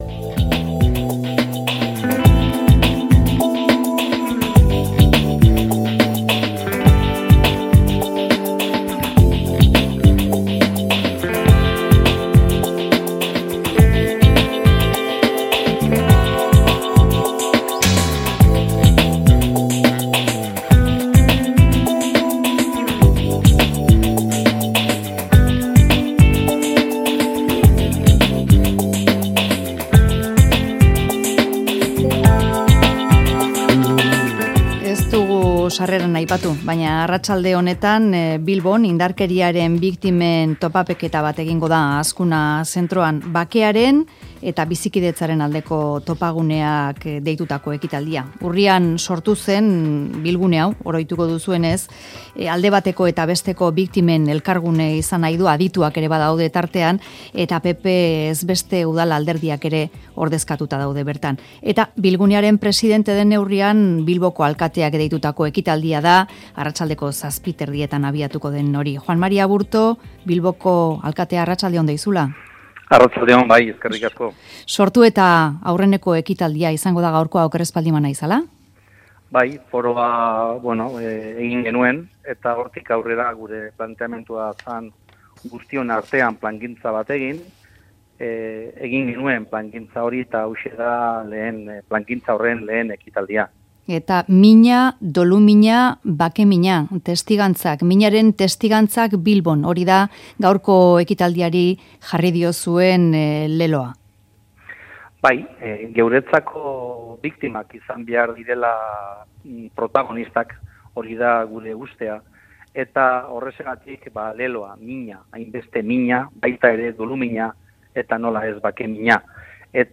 Audioa: Bilbon urrian sortutako biktimen bilguneak topaketa egin du Bilboko Azkuna Zentroan. Juan Mari Aburto Bilboko alkateak egin du Mezularian diskrezioz egin nahi izaten duten ekitaldiaren aurrerapena.